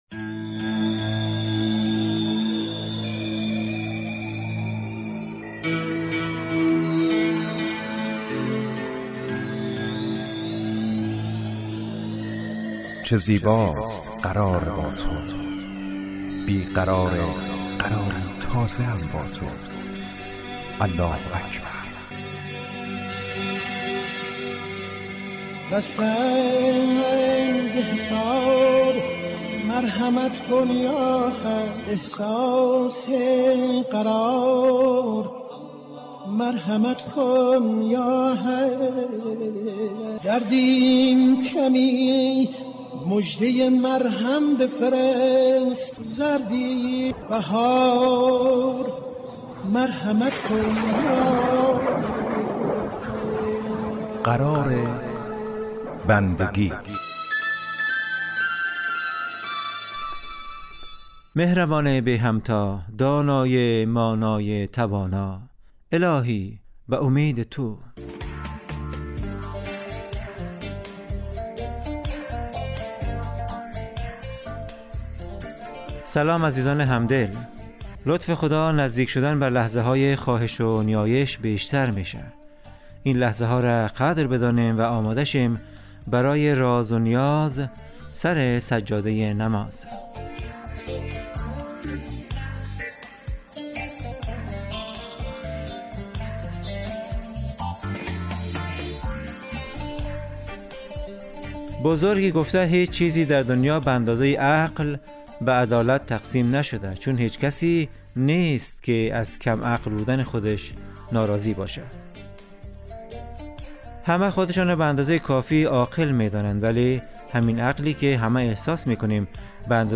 قرار بندگی برنامه اذانگاهی در 30 دقیقه هر روز ظهر پخش می شود.